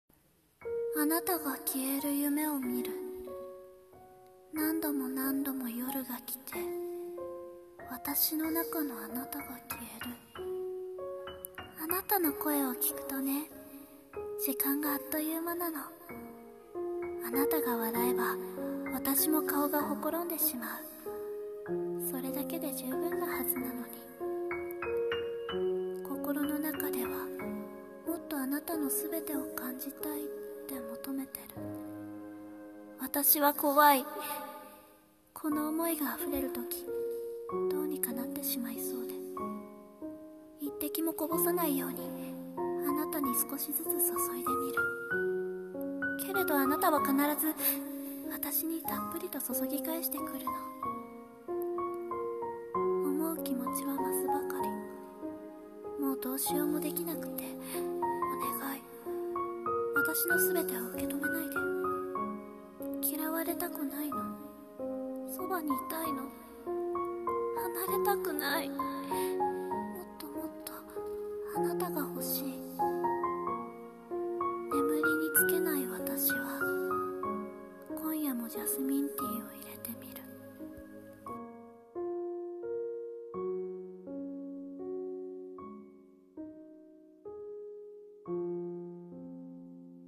【声劇】 ジャスミンティー